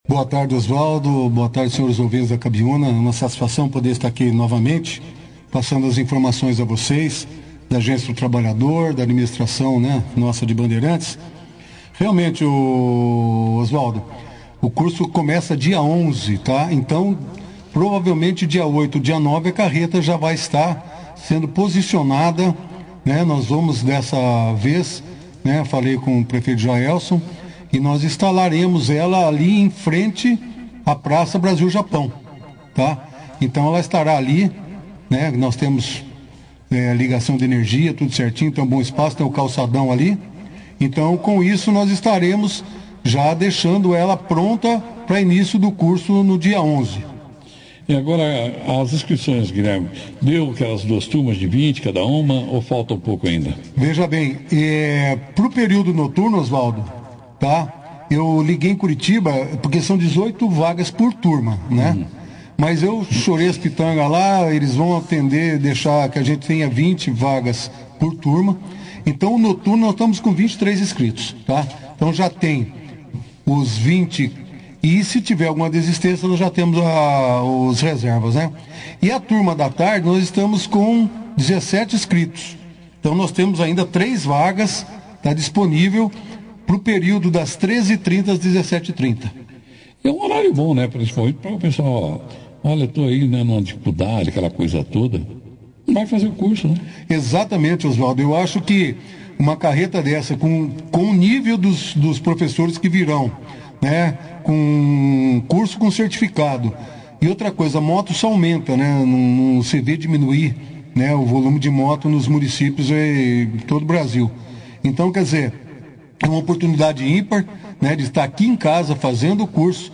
O Secretário do Trabalho e diretor gerente da Agência do Trabalhador de Bandeirantes, Guilherme Meneghel, participou da 2ª edição do jornal Operação Cidade, falando sobre vários assuntos relacionados ao trabalho e ao desenvolvimento da comunidade. Um dos principais tópicos abordados foi o projeto “Carretas do Conhecimento”, uma parceria entre o Senai, o Governo do Estado e a Volkswagen do Brasil.